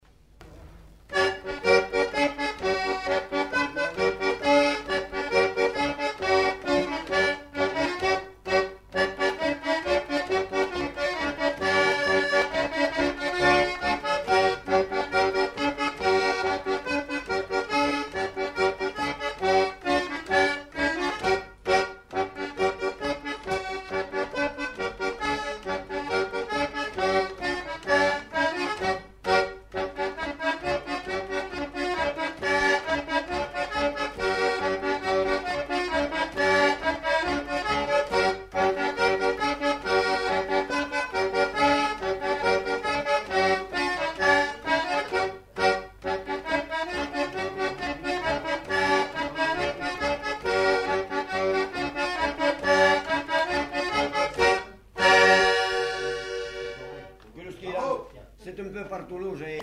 Lieu : Pyrénées-Atlantiques
Genre : morceau instrumental
Instrument de musique : accordéon diatonique
Danse : quadrille (5e f.)